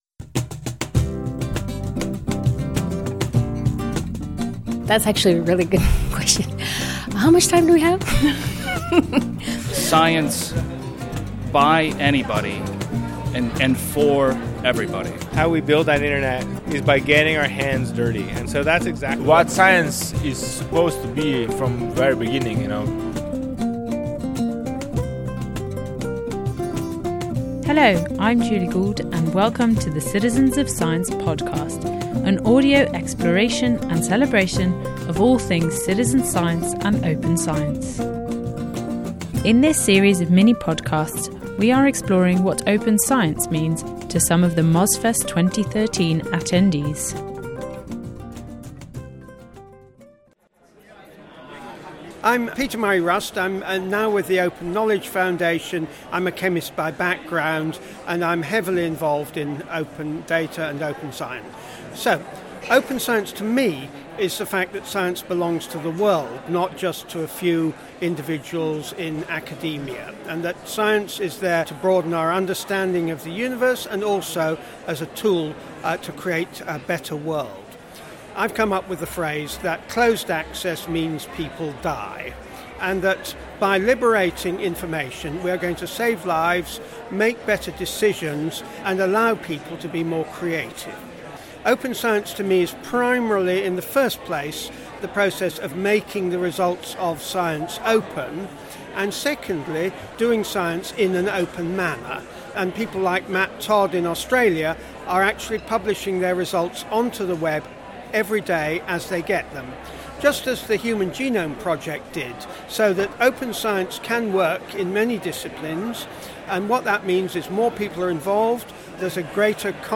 An audio exploration of what Open Science means to some of the attendees of Mozfest 2013.